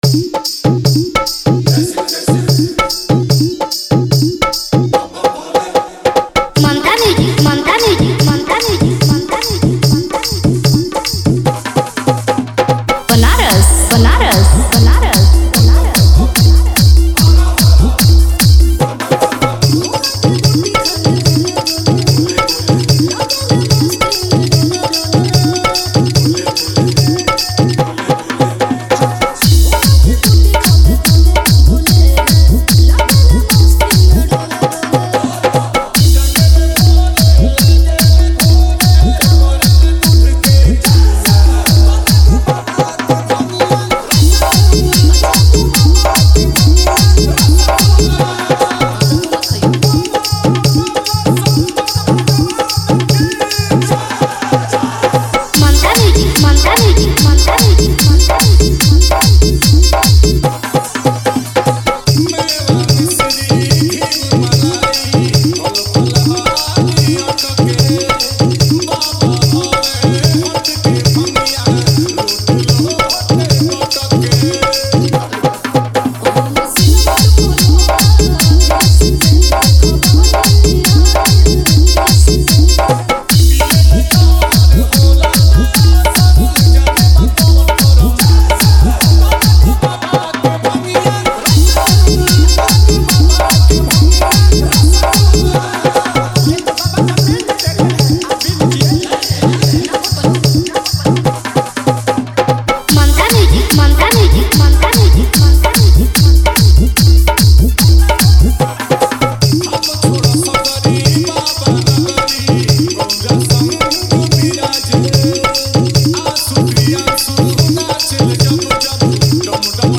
Category : dj remix songs bhojpuri 2025 new